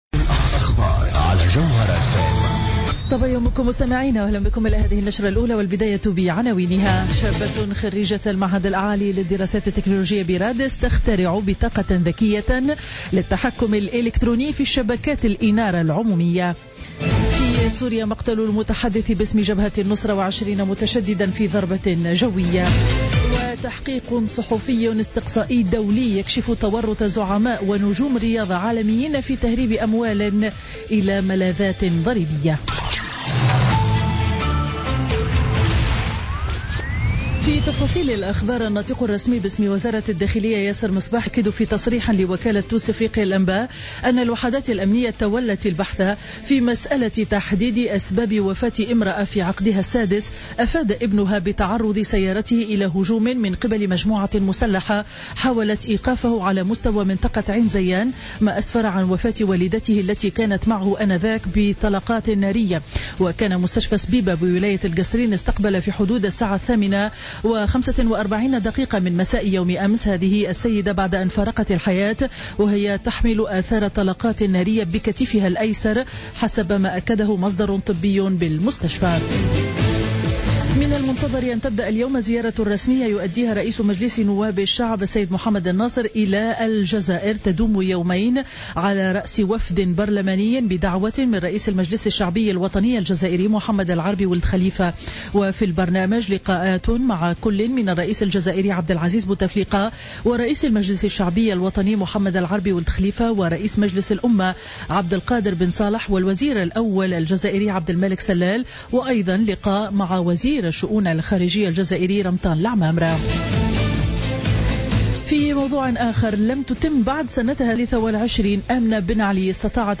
نشرة أخبار السابعة صباحا ليوم الاثنين 4 أفريل 2016